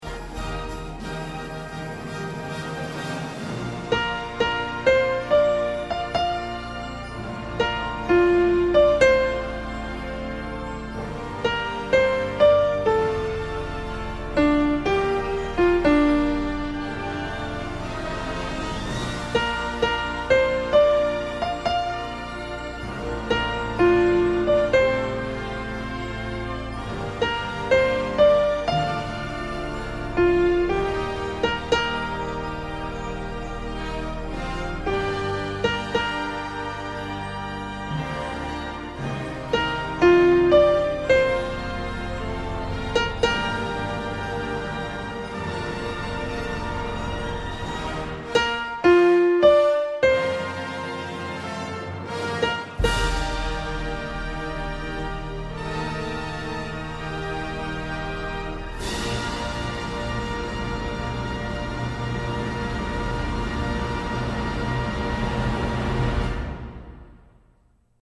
When You Believe – Soprano | Ipswich Hospital Community Choir